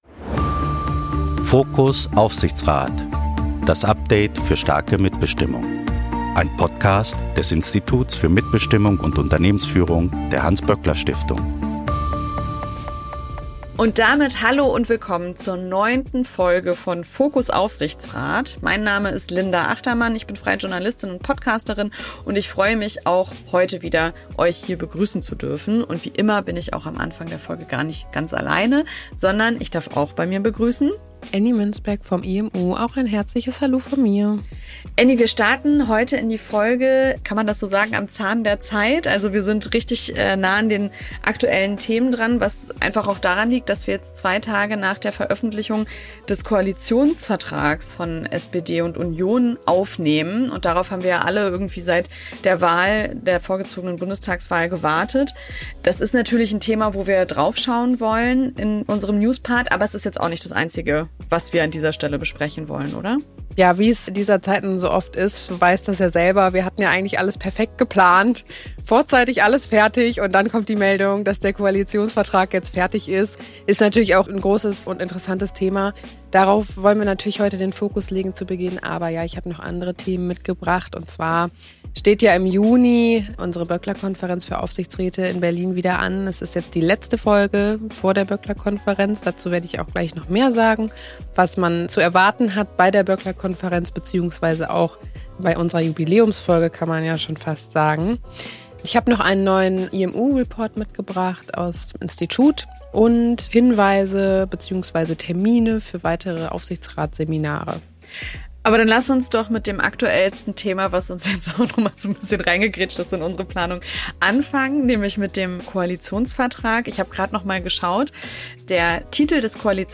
Fachinterview